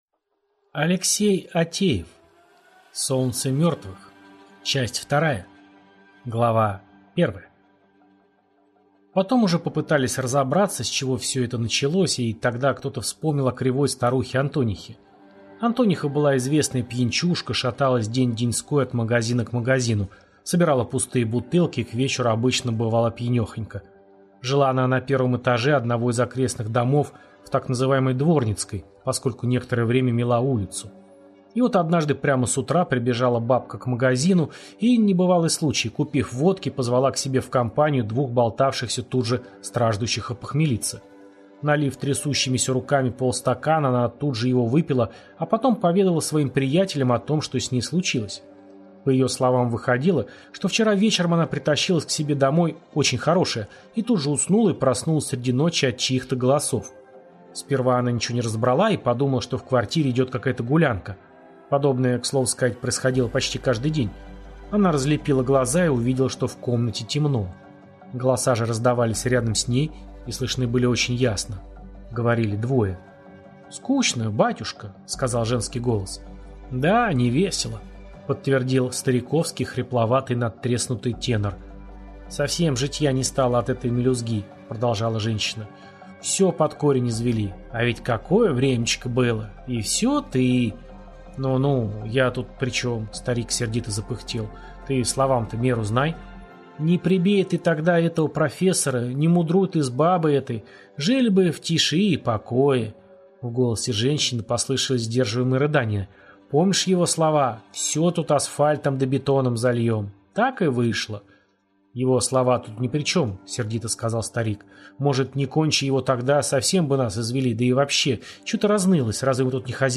Аудиокнига Новая загадка старого кладбища | Библиотека аудиокниг